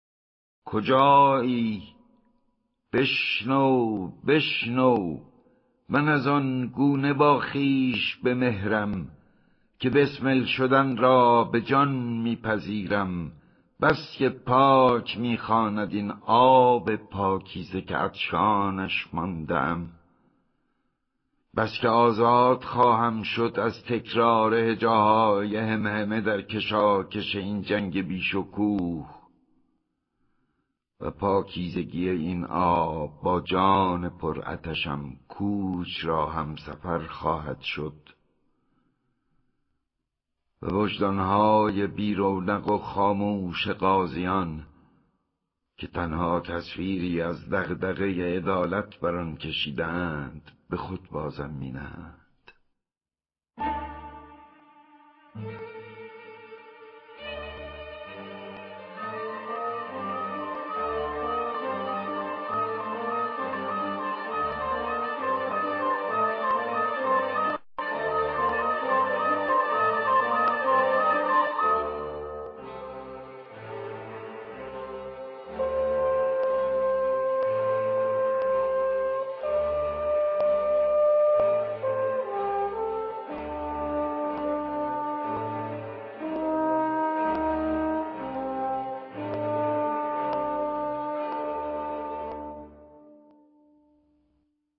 گوینده :   [احمد شاملو]